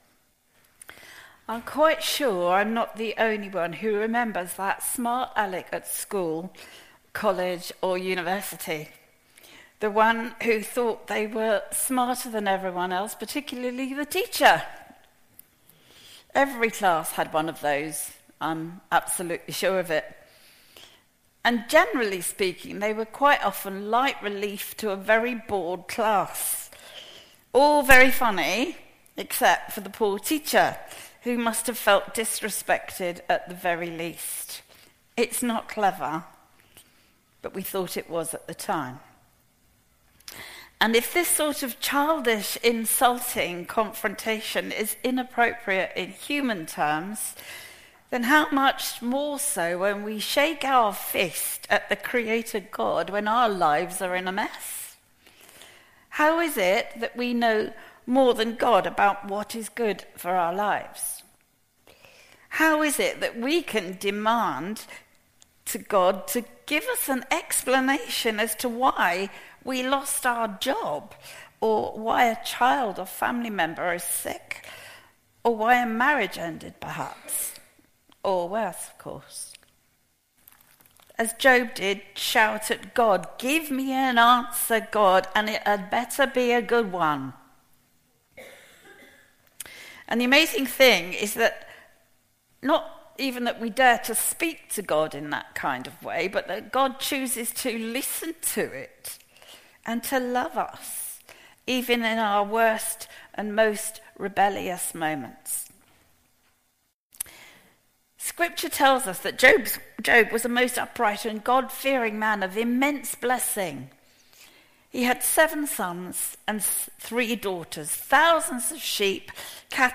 An audio version of the sermon is also available.
20-10-sermon-cropped.mp3